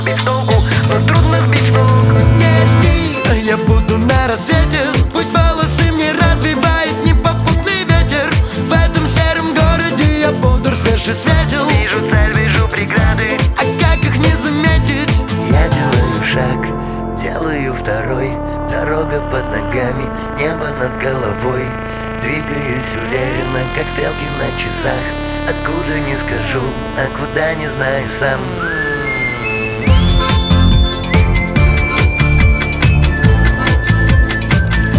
Alternative